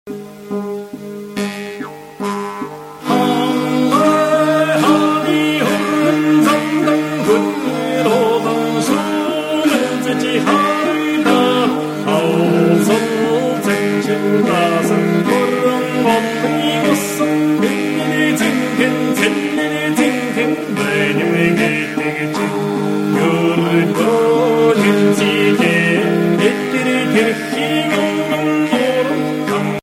- Traditional songs of Mongolia
- khun tovshuur (string instrument)
- tsuur, aman tsuur (wind instrument)
- hel khuur (Jew's wooden harp)
- tömör hel khuur (Jew's brass harp)
- morin khuur - (string instrument - horse-violin)